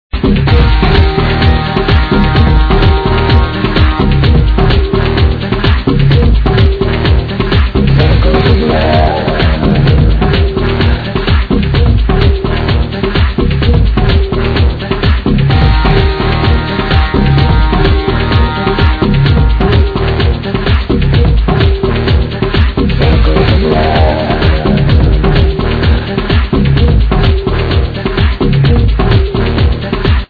I remember the same effects on same vocal !